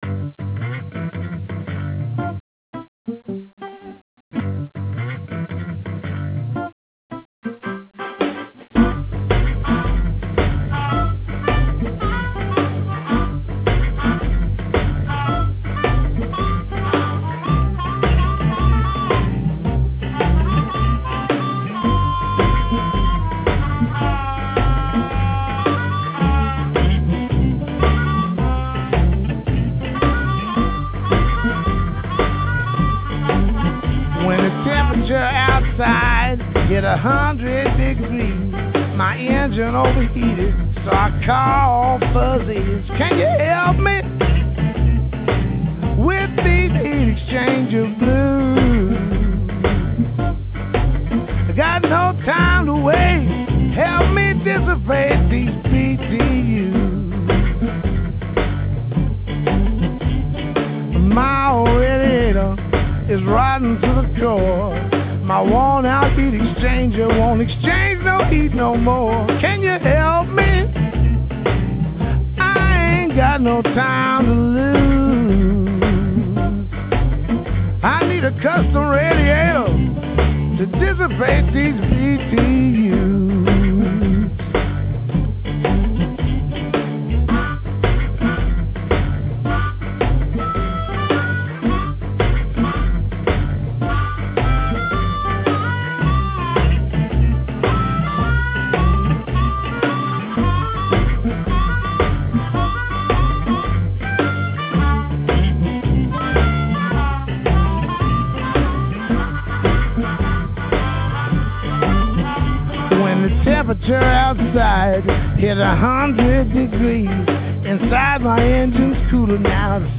finger-picked style guitar
harmonica, mandolin, didlio-bo
a Farmer foot drum